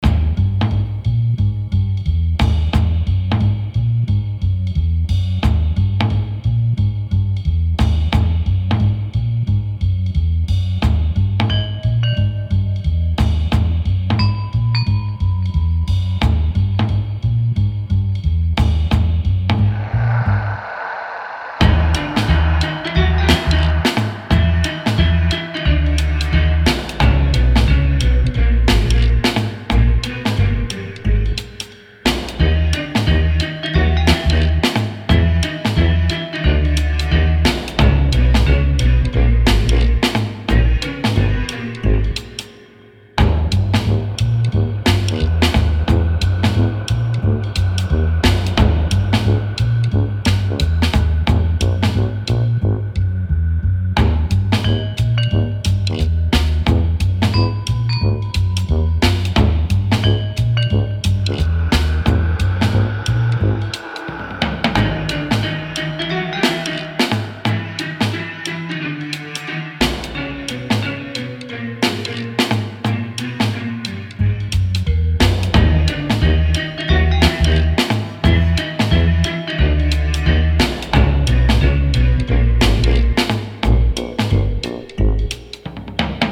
Mysterious, mischievious dark jazz piece